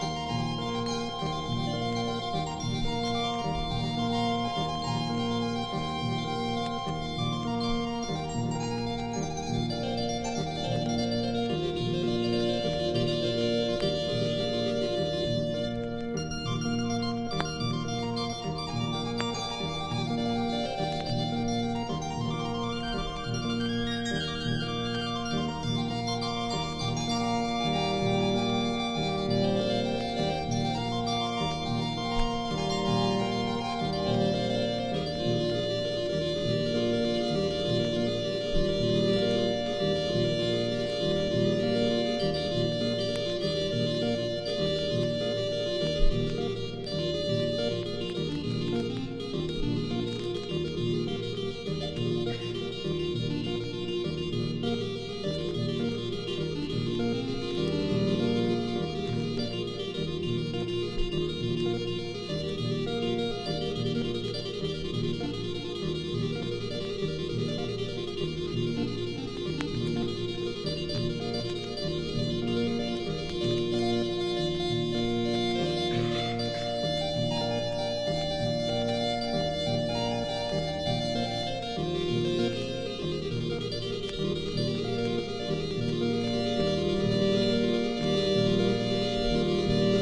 （未開封シールド盤）渋いバラード・ナンバー！！